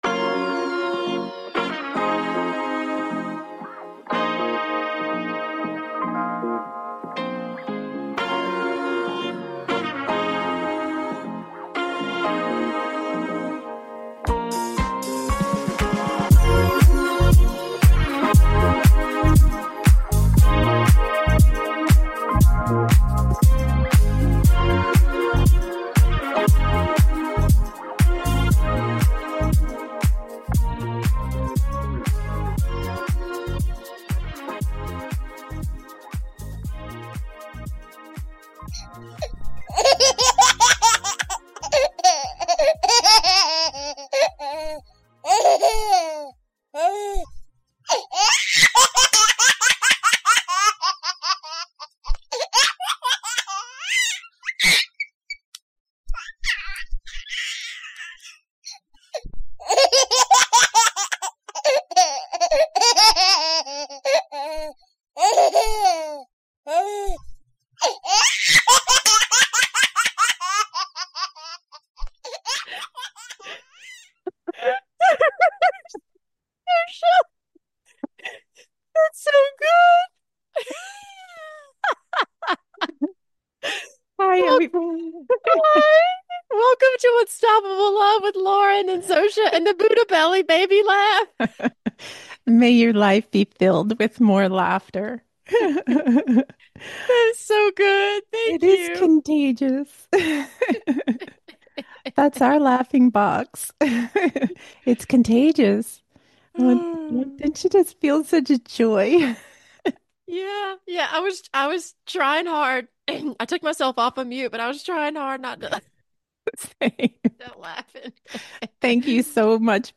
CLICK HERE SUBSCRIBE TO TALK SHOW